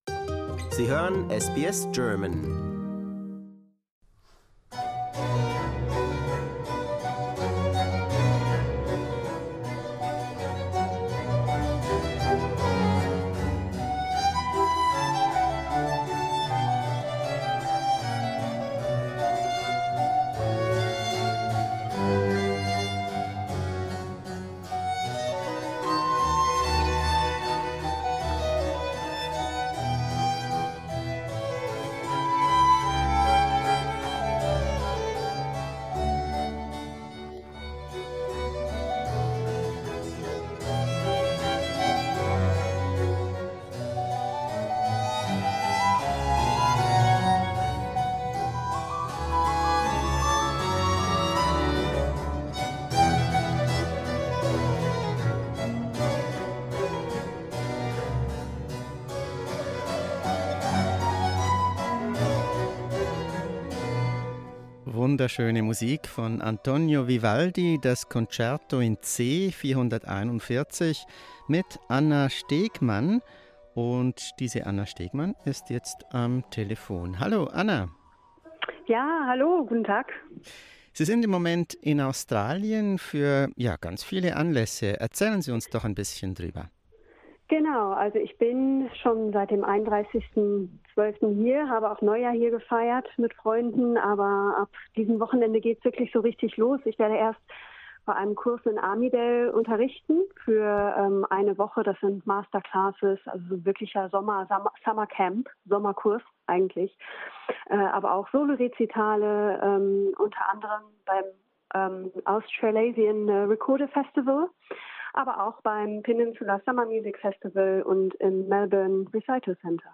From Vivaldi, Bach and Telemann to contemporary composers like Hosokowa and Yun: the repertoire of classical music for recorder is richer than one might think - and that is a good thing. Learn more in an interview